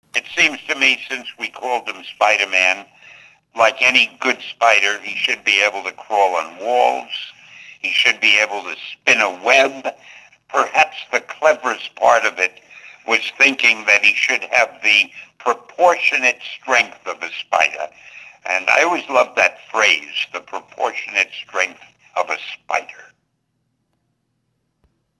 Unsterbliche Worte von Stan Lee (mp3):